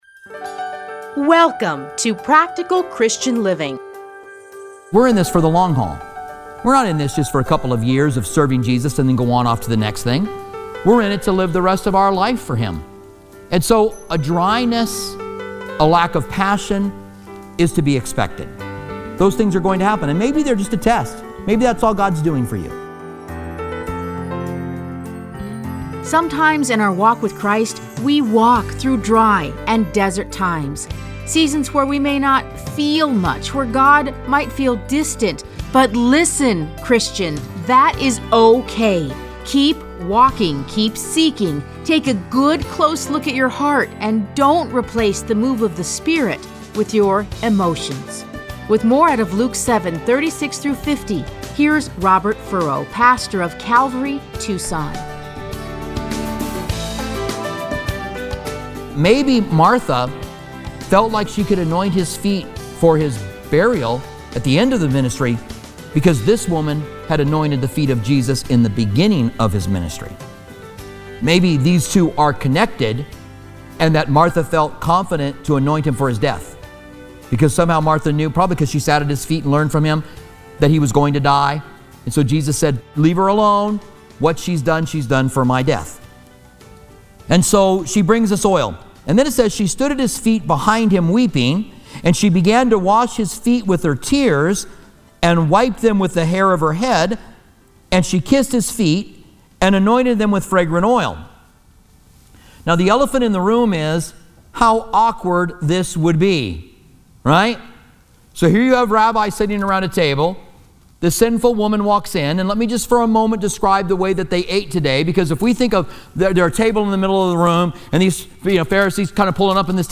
Listen to a teaching from Luke 7:36-50.